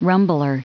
Prononciation du mot rumbler en anglais (fichier audio)
Prononciation du mot : rumbler